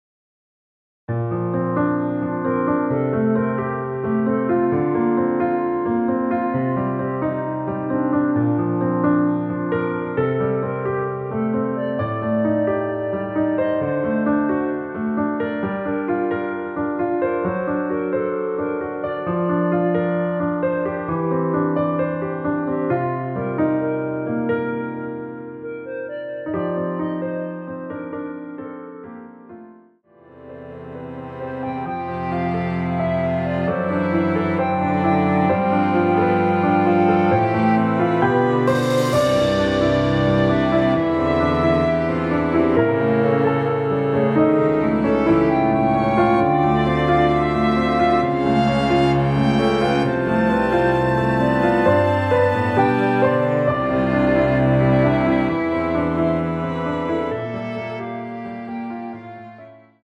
원키에서(-6)내린 멜로디 포함된 MR입니다.
Bb
앞부분30초, 뒷부분30초씩 편집해서 올려 드리고 있습니다.
중간에 음이 끈어지고 다시 나오는 이유는